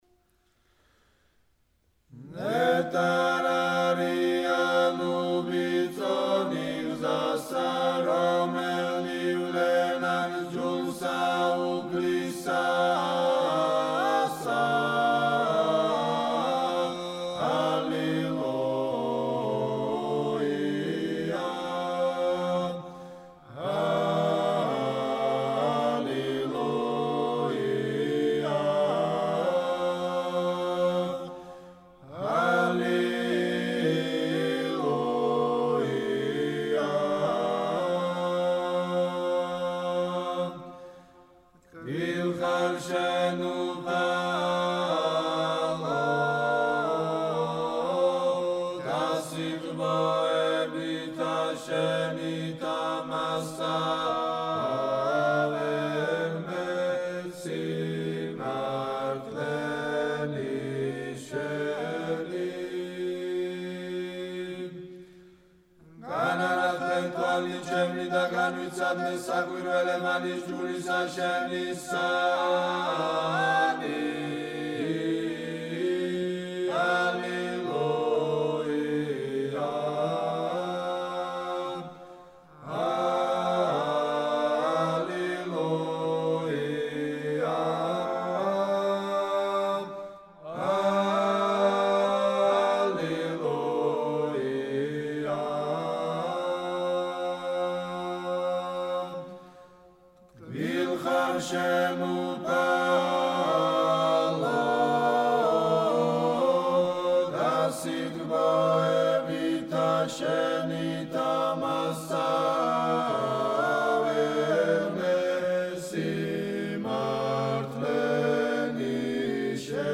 საგალობელი: ნეტარ არიან - მამა დავითის ტაძრის გუნდი - გელათის სკოლა - საგალობლის ტექსტი